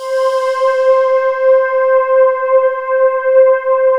Index of /90_sSampleCDs/USB Soundscan vol.28 - Choir Acoustic & Synth [AKAI] 1CD/Partition C/08-FANTASY